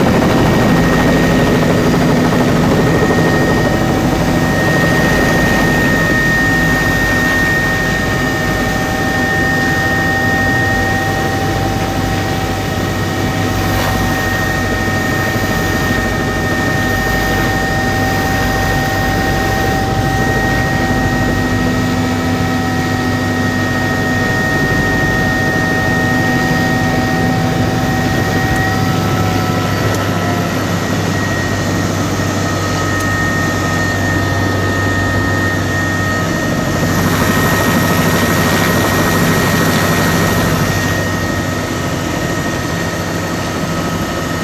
transport
Helicopter Int Minor Rpm Variations In Flight